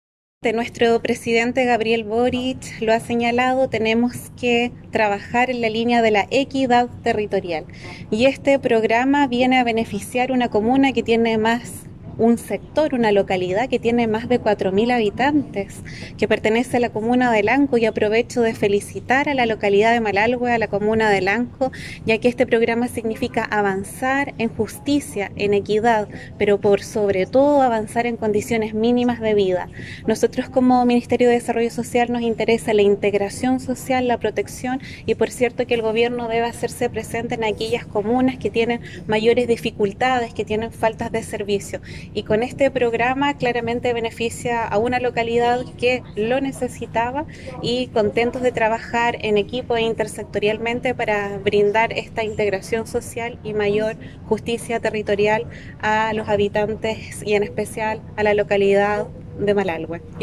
En tanto la seremi de Desarrollo Social, Vanessa Huiquimilla, señaló que, “este programa viene a beneficiar un sector que tiene más de cuatro mil habitantes, y este programa nos permite avanzar en justicia y equidad social, y condiciones mínimas de vida”.
Seremi-Vanessa-Huiquimilla_-Pequenas-Localidades.mp3